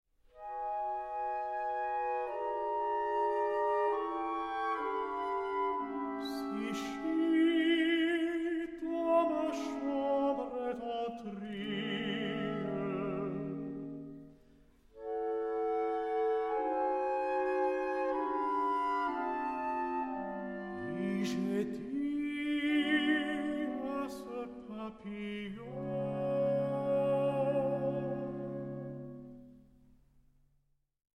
Tenor
The four ‘symphonic poems for voice and orchestra’